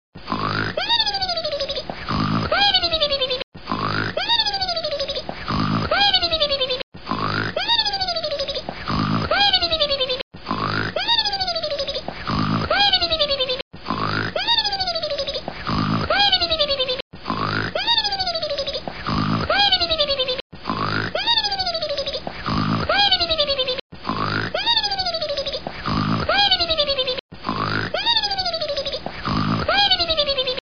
snore.mp3